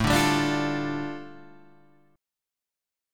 A7sus4#5 chord